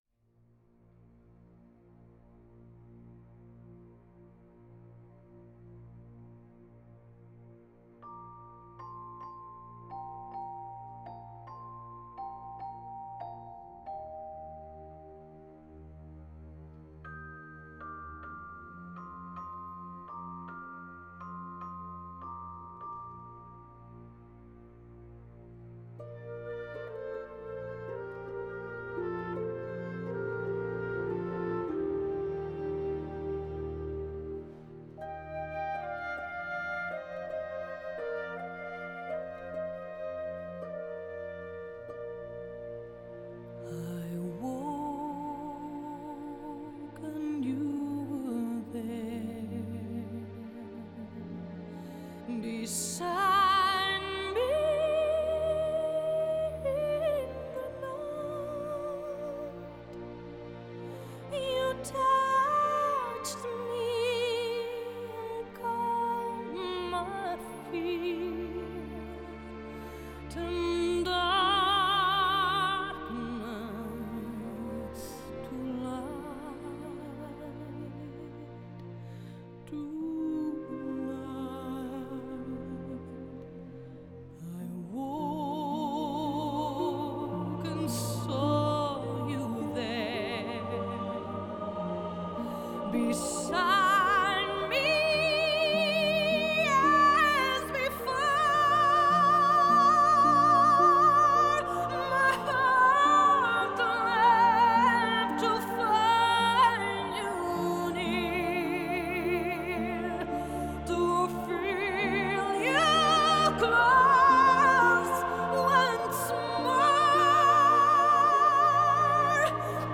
Genre: Score
編寫全新的管弦樂曲